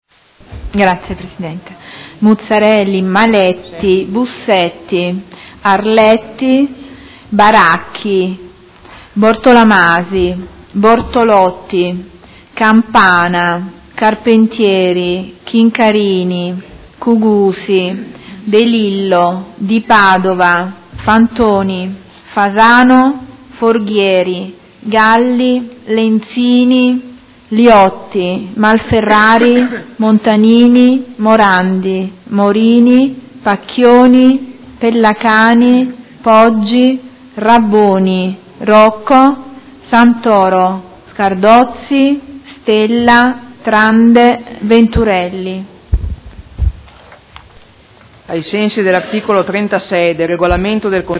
Seduta del 26 gennaio. Appello